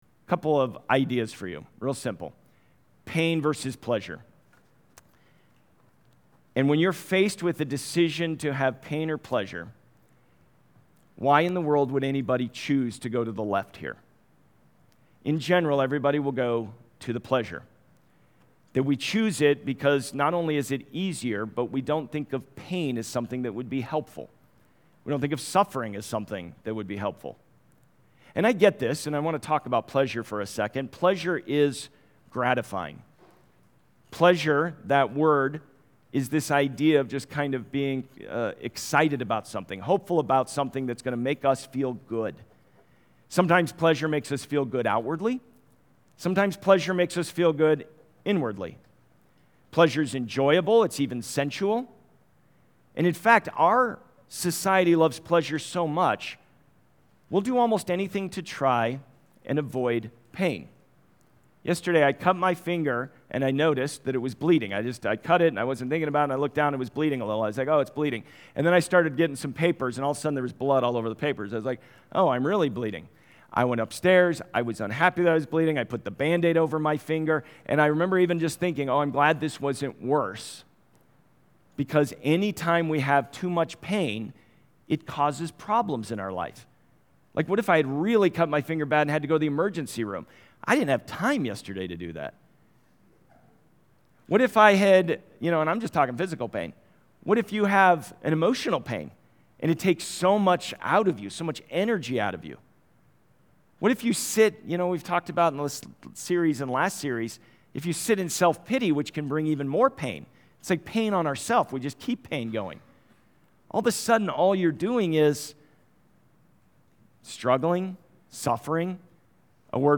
Watch or listen to recent Sunday messages and series from The Journey Church in Westminster, CO. New sermons posted weekly with video and notes.